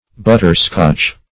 Search Result for " butter-scotch" : The Collaborative International Dictionary of English v.0.48: Butter-scotch \But"ter-scotch`\, n. A kind of candy, mainly composed of sugar and butter.
butter-scotch.mp3